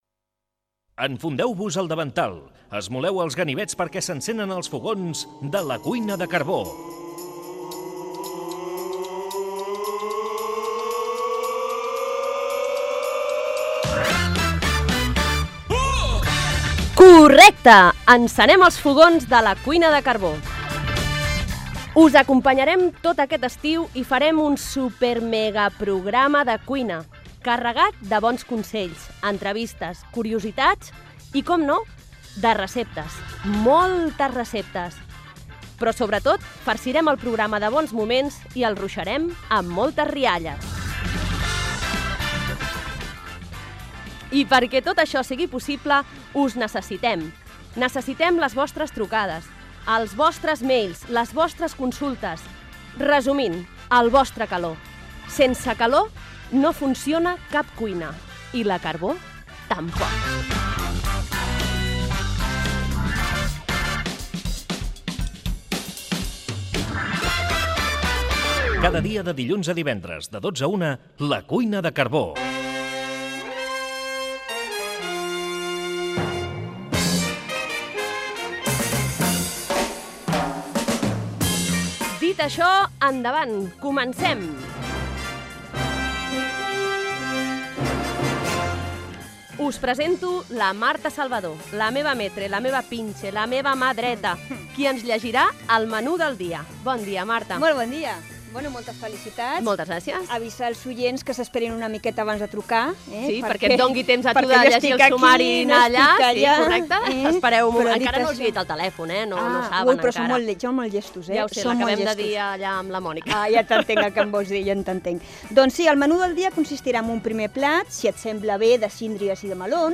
Careta del programa sobre cuina i gastronomia. Continguts, invitació a la participació, indicatiu del programa i sumari
FM